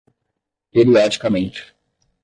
Pronunciado como (IPA) /pe.ɾiˌɔ.d͡ʒi.kaˈmẽ.t͡ʃi/